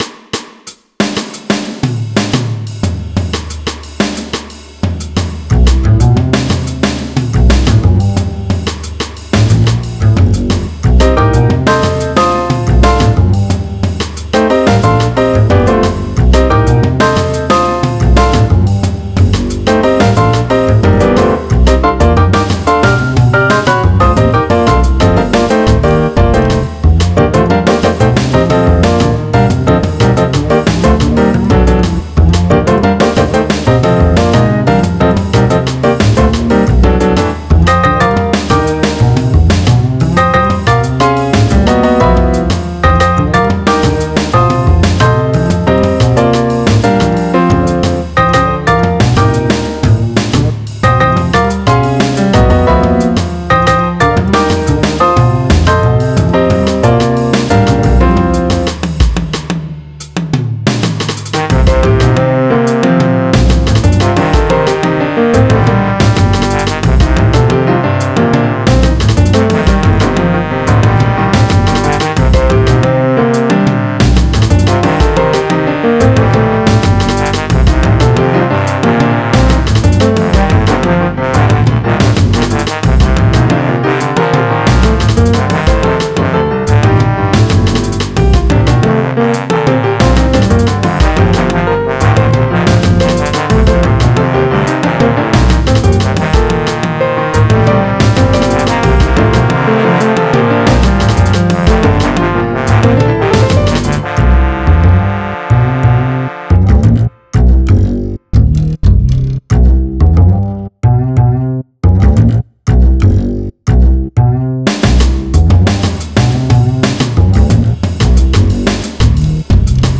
jazz , rhythmic